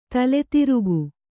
♪ tale tirugu